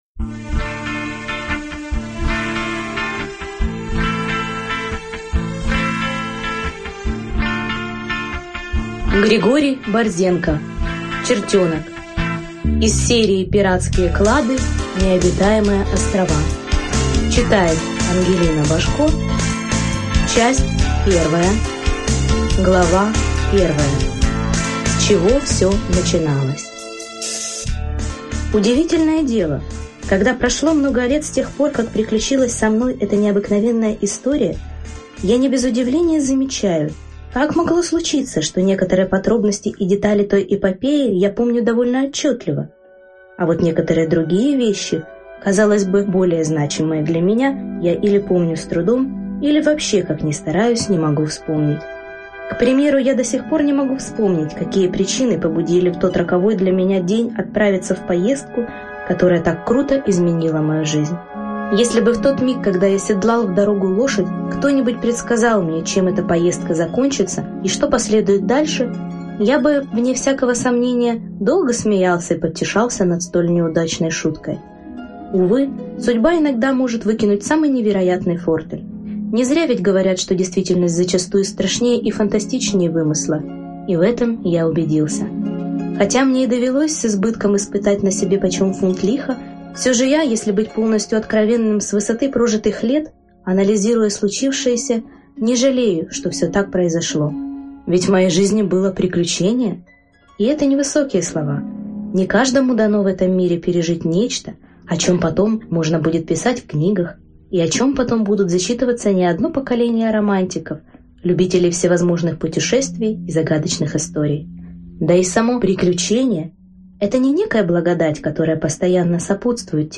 Aудиокнига Чертенок